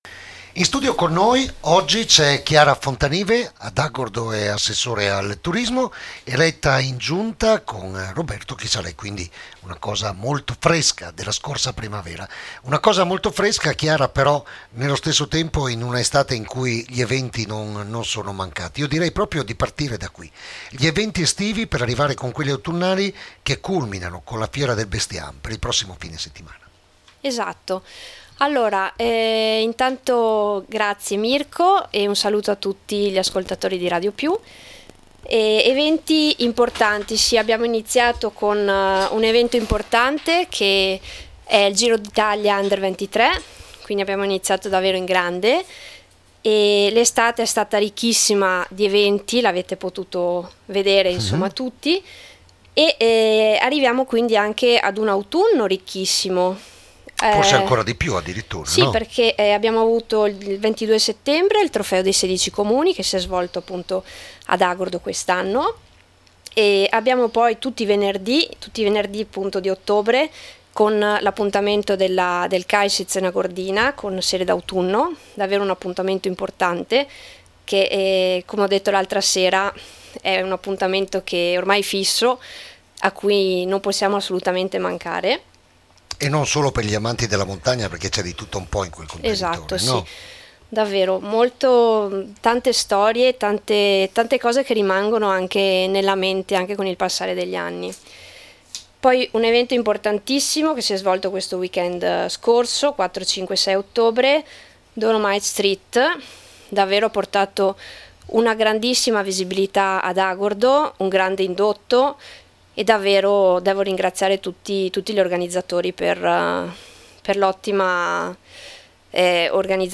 L’INTERVISTA ALL’ASSESSORE AL TURISMO DEL COMUNE DI AGORDO, CHIARA FONTANIVE
IERI ALLA RADIO